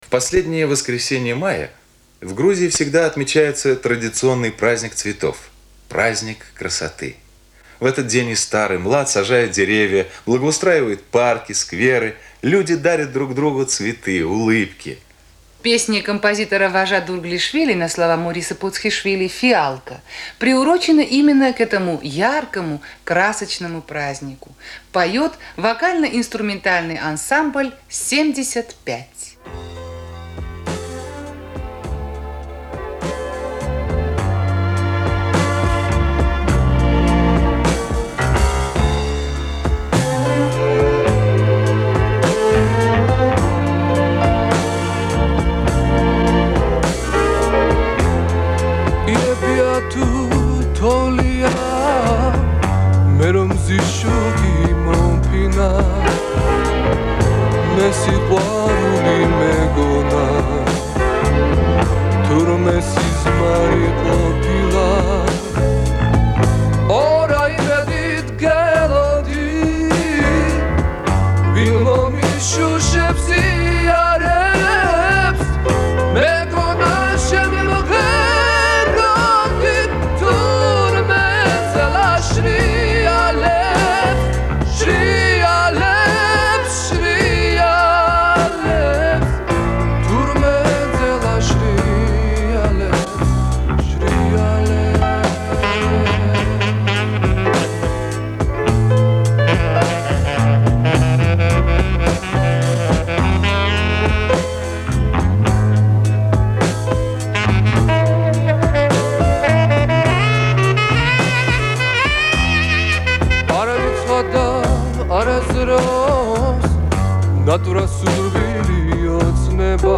Записано с эфира летом 1980 года.
Оцифровка записей с катушек